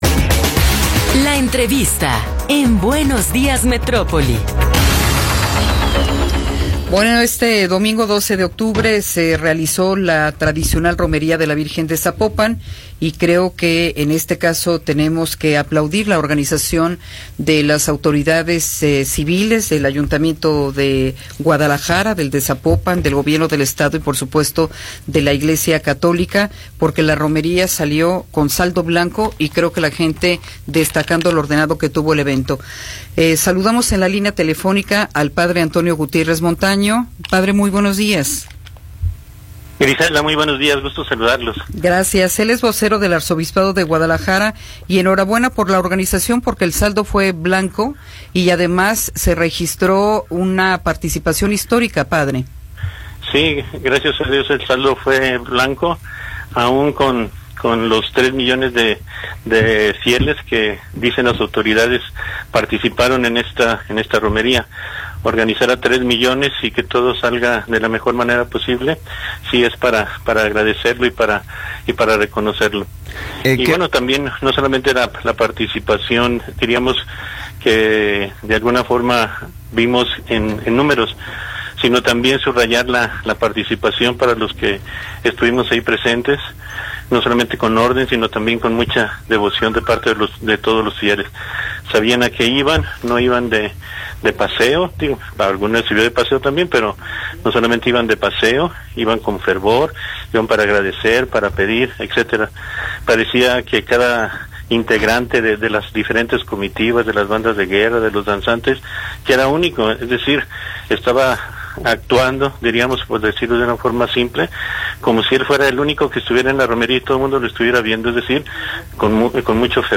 Entrevistas
entrevista-3.m4a